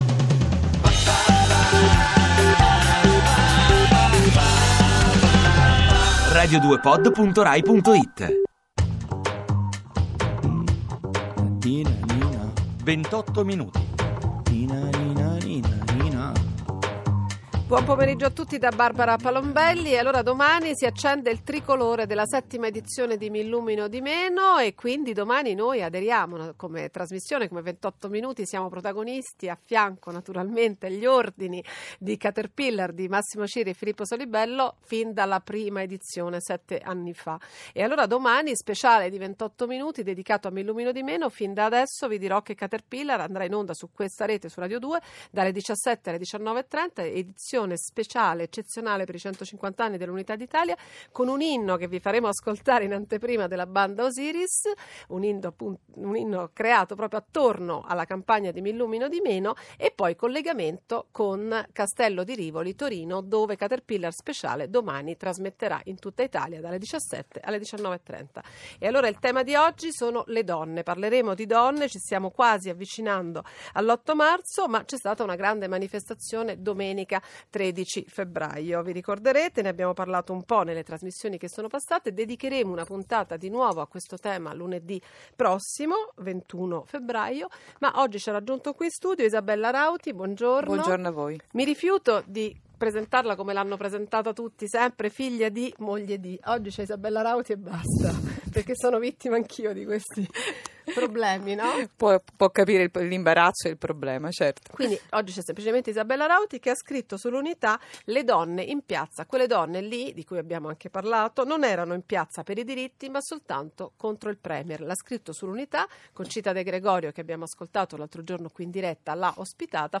28minuti (Radio Due Rai) – Intervistata da Barbara Palombelli